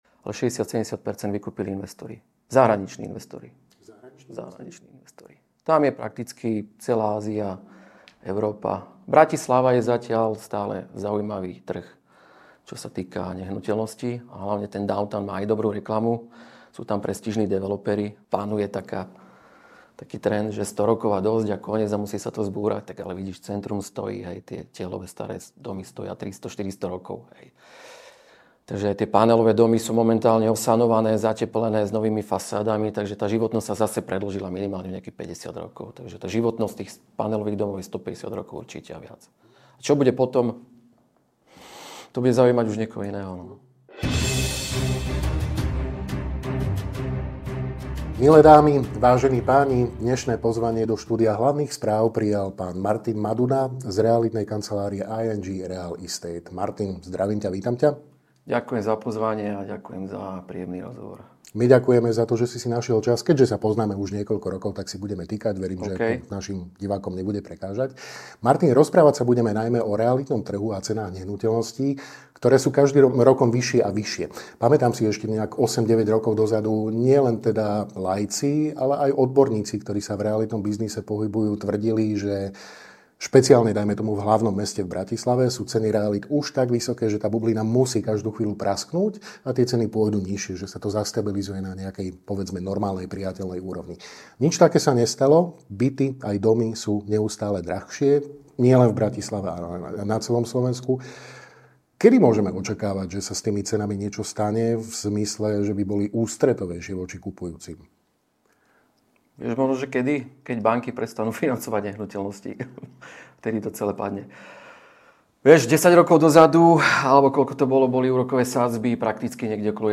Viac vo video rozhovore.